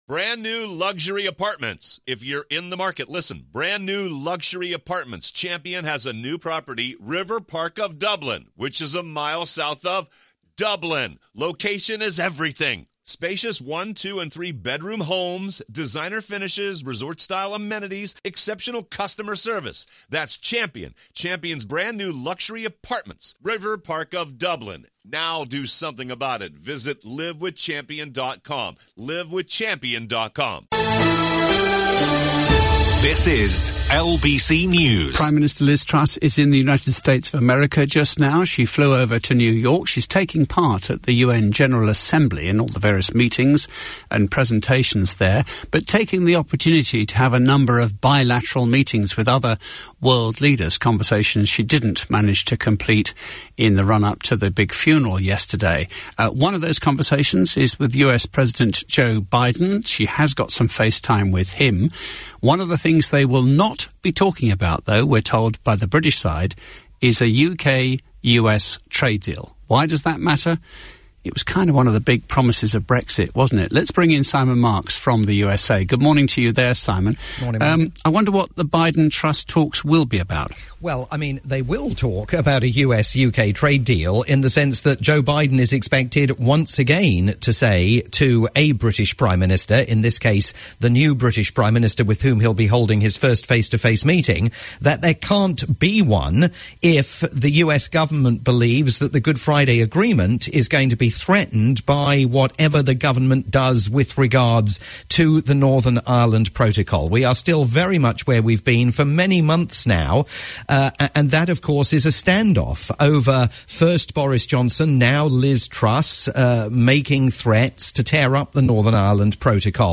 live roundup